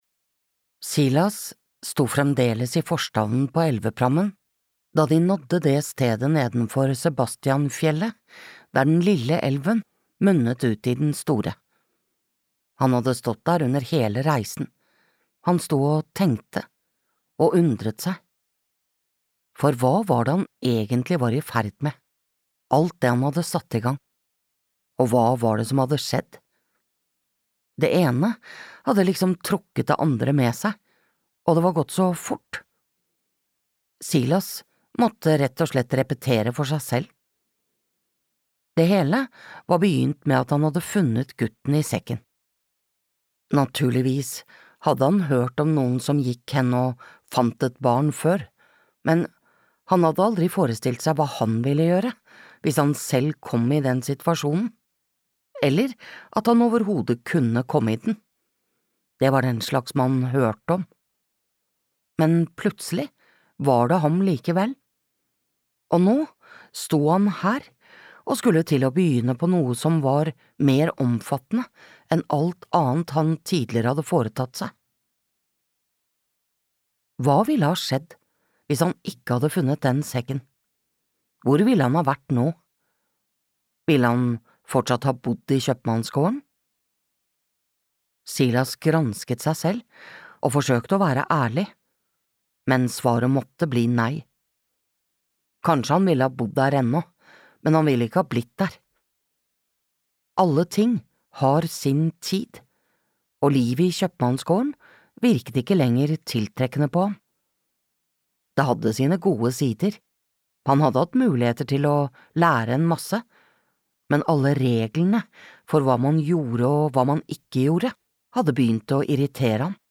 Silas på Sebastianfjellet (lydbok) av Cecil Bødker